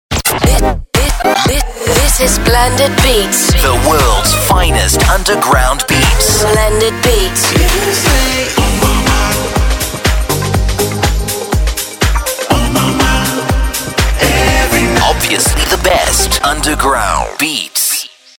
Werbesprecher
Neutral